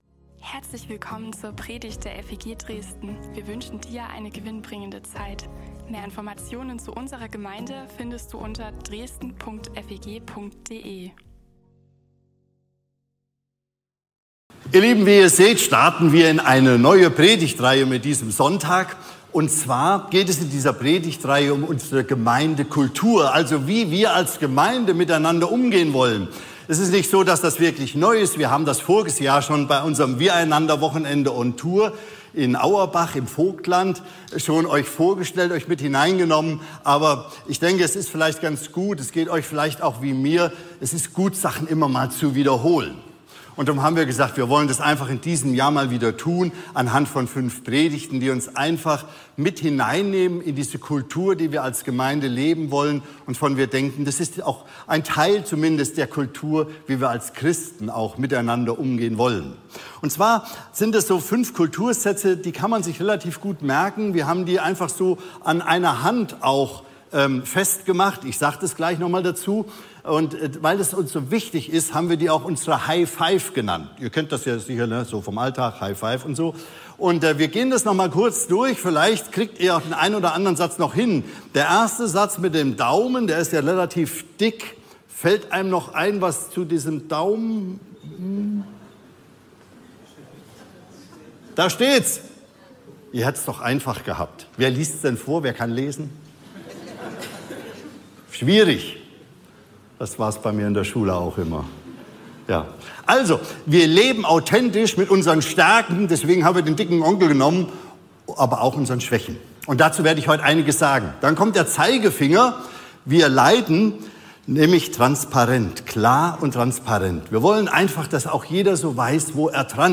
Predigten und mehr